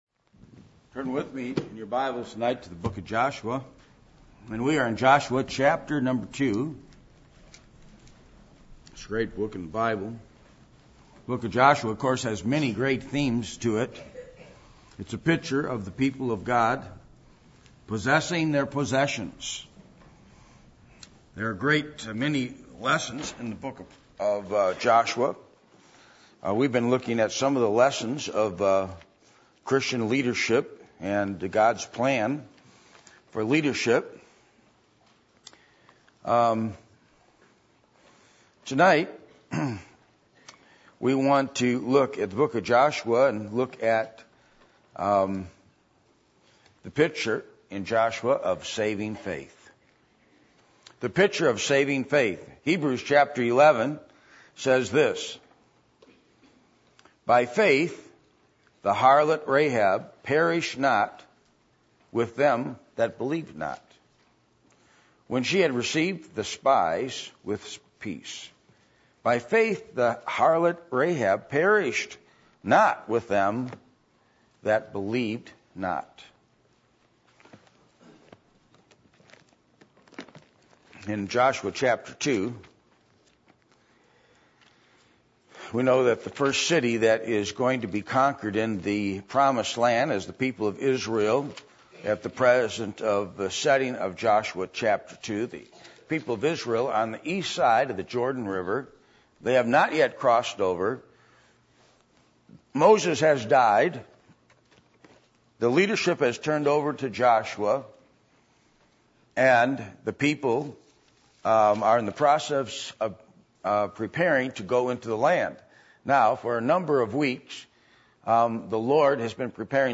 Passage: Joshua 2:1-24 Service Type: Sunday Evening %todo_render% « Jesus